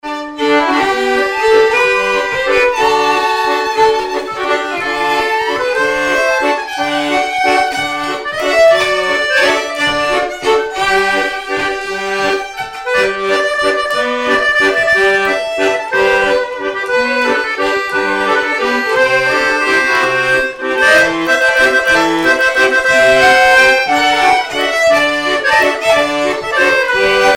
danse : valse
violon
Pièce musicale inédite